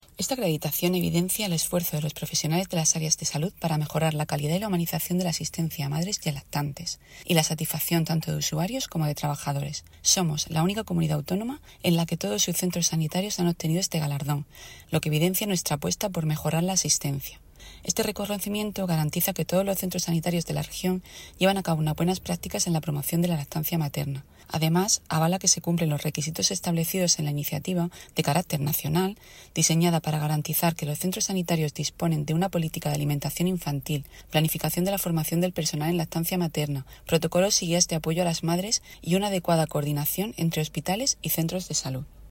Declaraciones de la gerente del SMS, Isabel Ayala, sobre la acreditación en humanización de los centros sanitarios regionales.